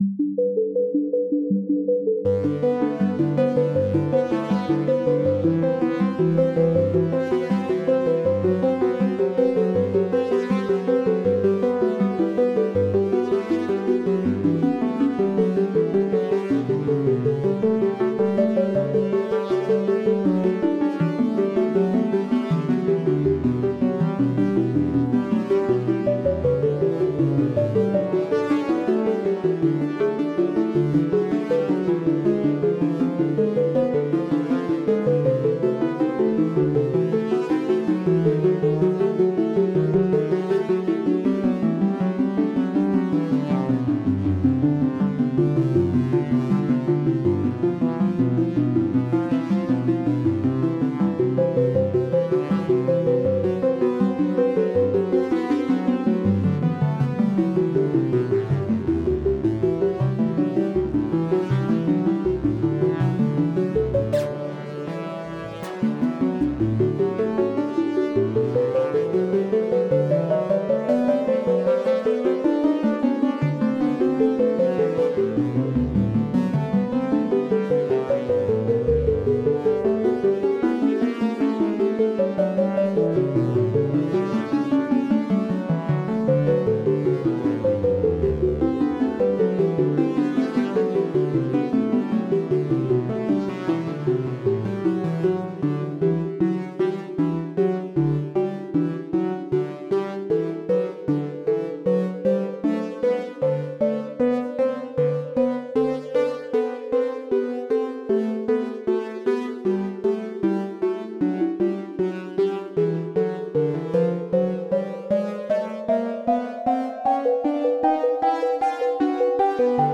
Bach Synthesizer